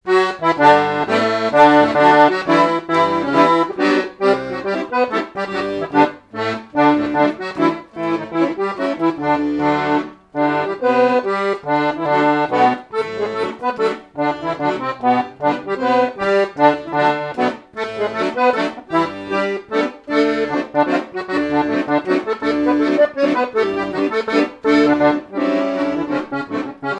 danse : scottich trois pas
airs de danses issus de groupes folkloriques locaux
Pièce musicale inédite